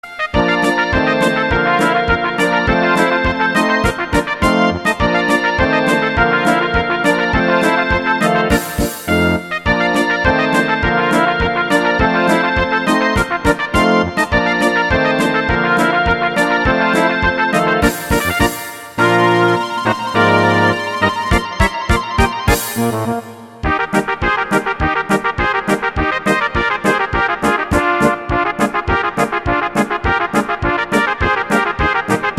Rubrika: Národní, lidové, dechovka
- polka
Karaoke